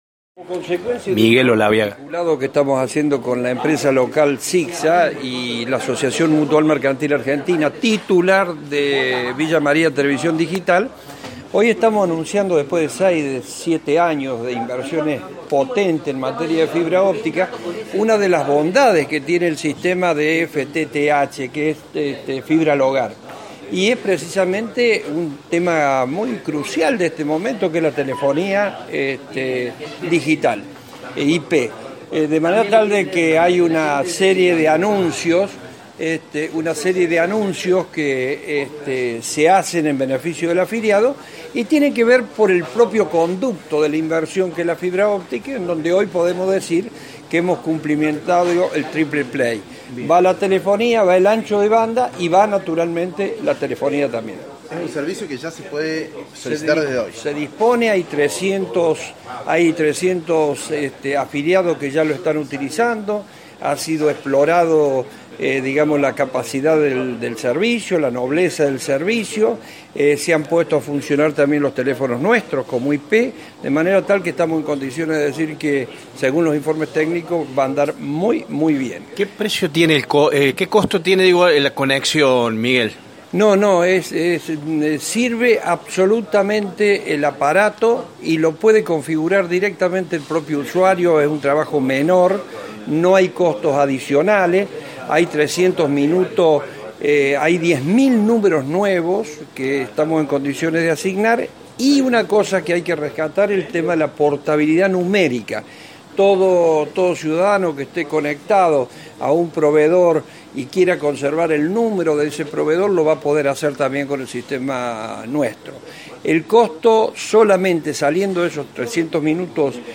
ofreció una conferencia de prensa